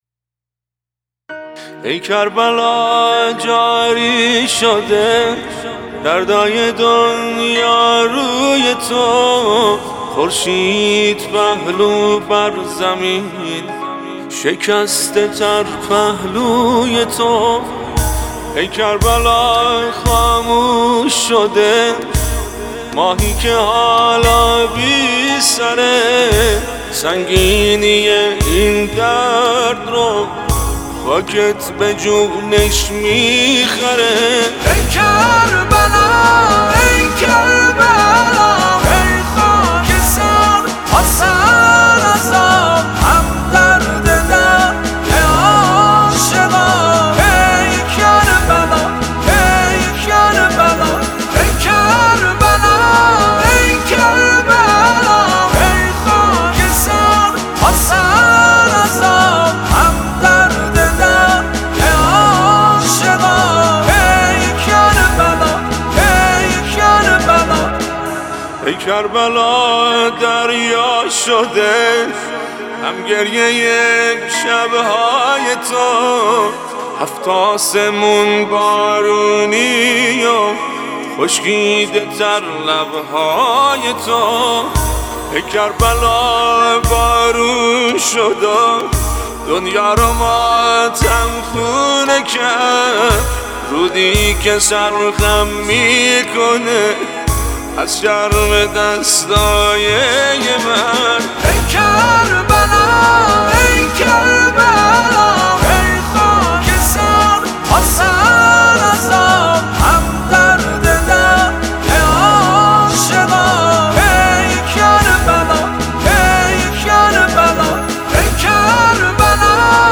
مداح و خواننده آذری زبان